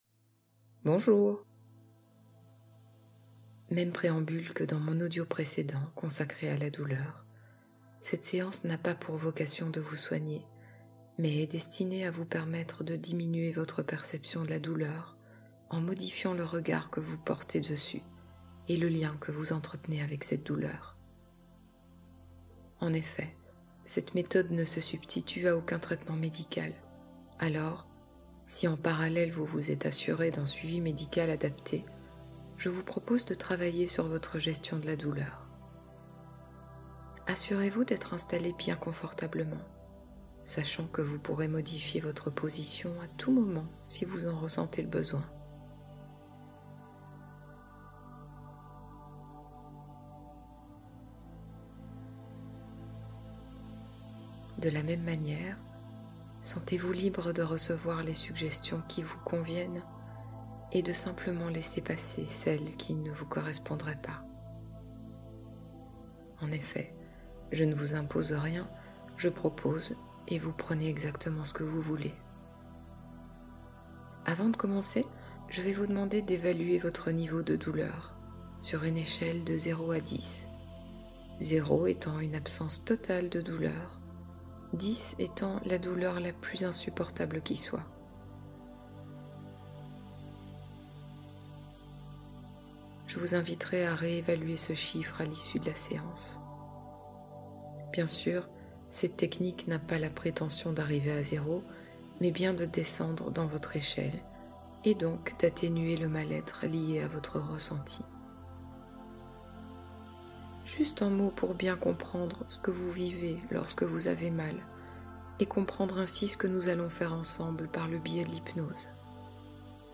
Esprit positif : rééquilibrage énergétique guidé